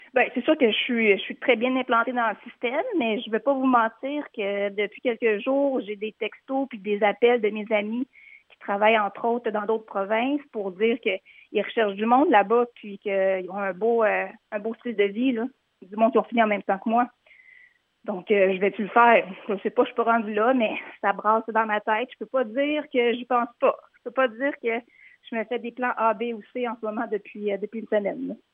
Nouvelles